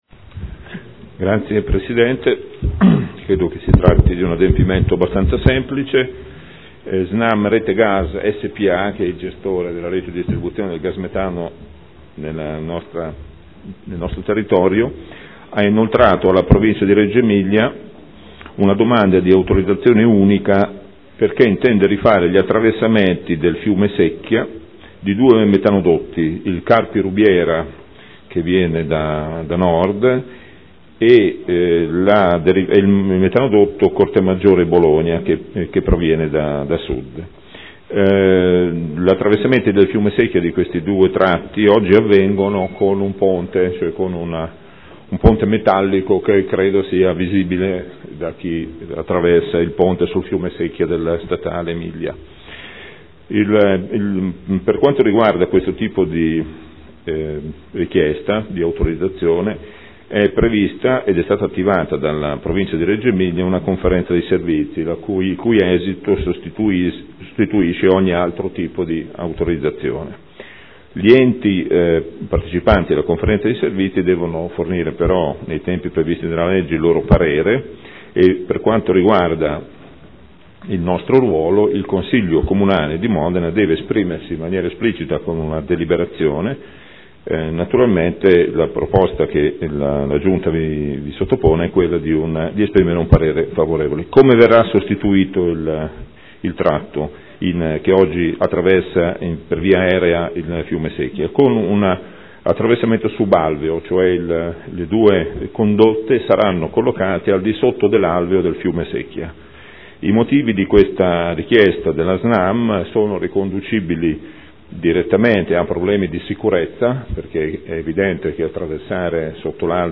Gabriele Giacobazzi — Sito Audio Consiglio Comunale
Seduta del 27/05/2013 Progetto di rifacimento e variazione ai metanodotti SNAM RETE GAS SPA – Parere favorevole a proposta di variante urbanistica